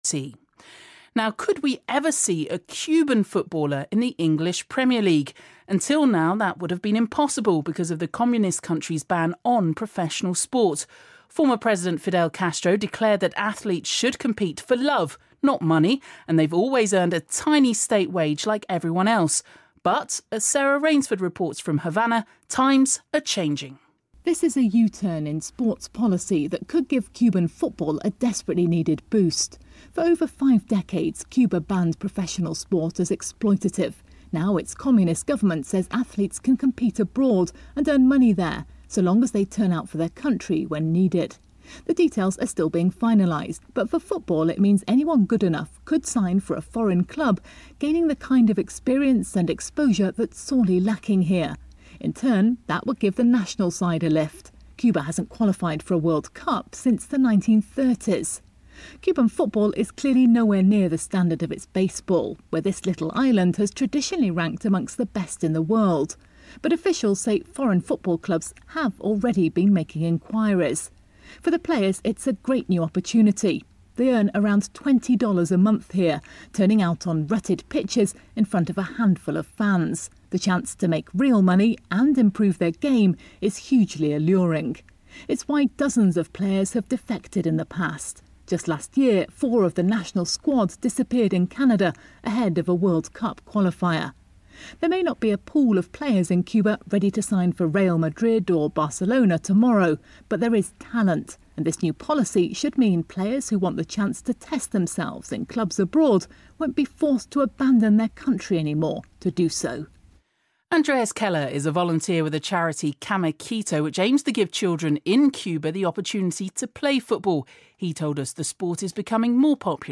Oktober 2013, Interview "Fussball in Kuba"